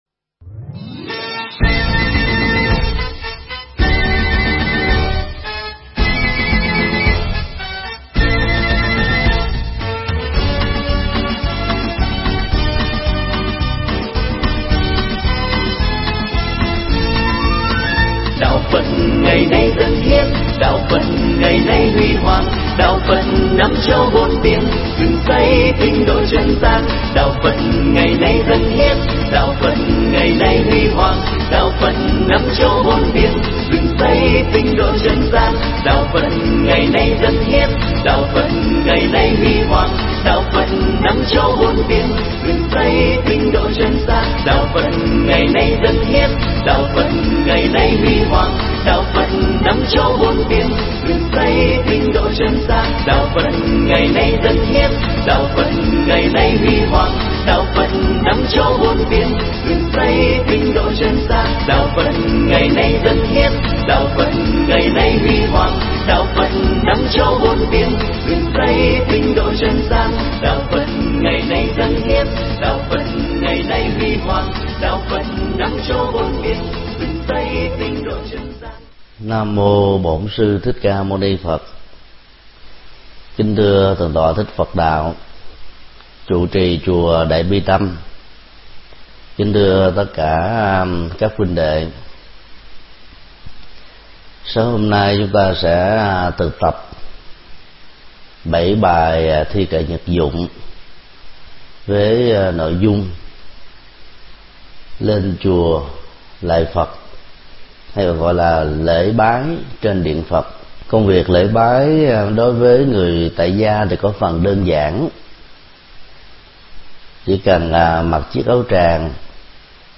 Mp3 Pháp Thoại Tỳ ni nhật dụng 03: Lên chùa lễ Phật – Thầy Thích Nhật Từ giảng tại chùa Đại Bi Tâm, Thụy Điển, ngày 23 tháng 6 năm 2011